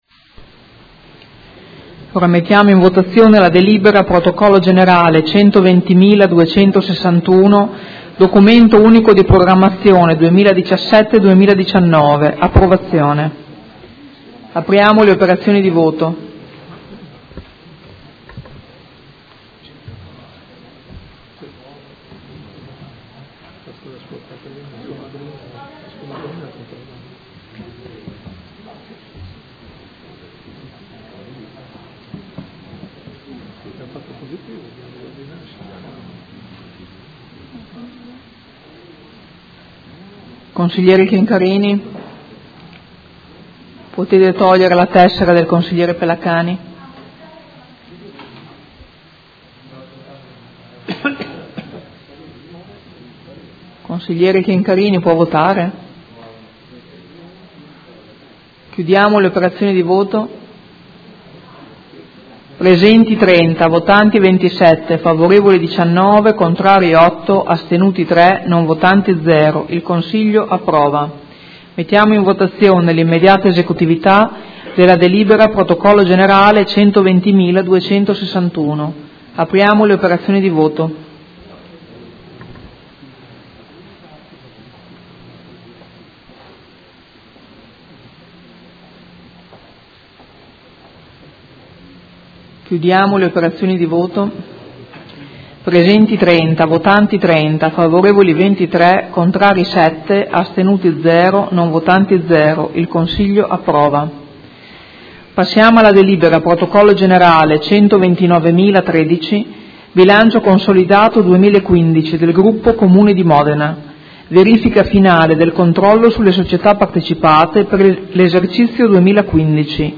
Seduta del 22/09/2016 Proposta di deliberazione: Documento Unico di Programmazione 2017-2019 - Approvazione. Votazione delibera ed immediata esecutività